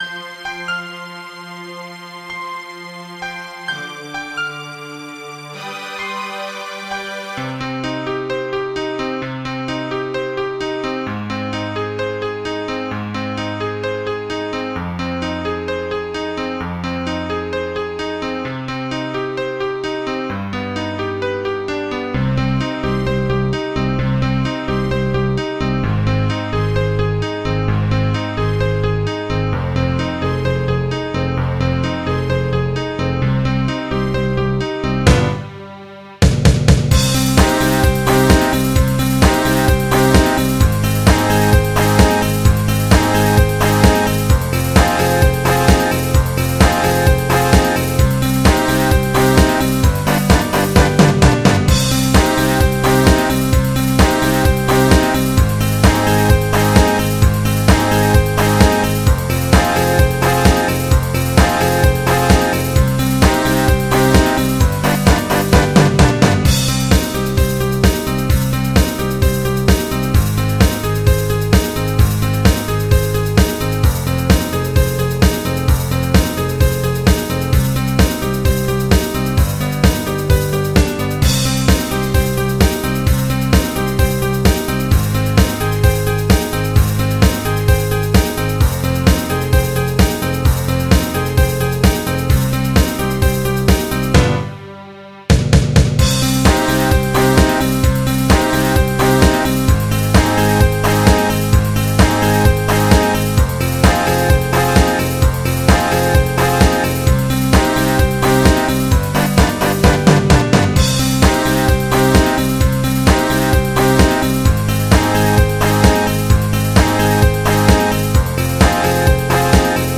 Прослушать минусовку